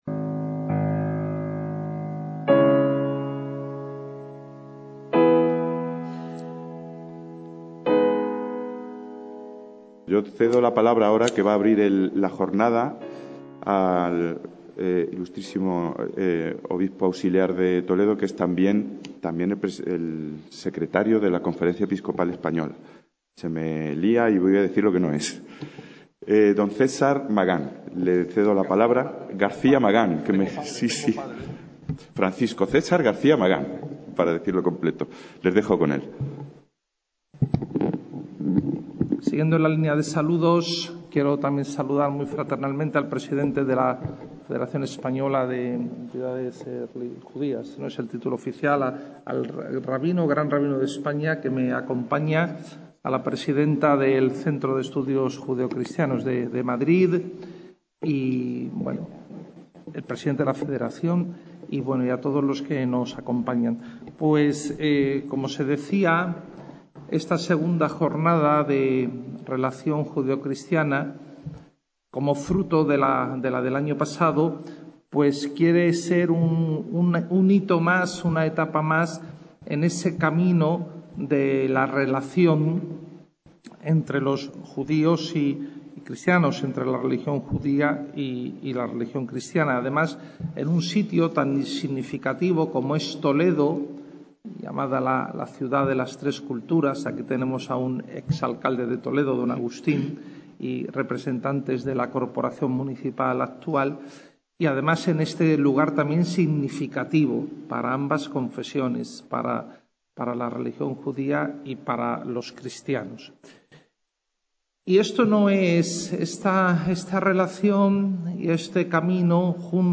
Espiritualidad, justicia social y paz (Sinagoga Mayor de Toledo, 7/4/2024)
II JORNADA DE AMISTAD JUDEO-CRISTIANA - La II Jornada de Amistad Judeo-Cristiana que se celebró en Toledo el pasado 7 de abril de 2024 fue inaugurada por Mons.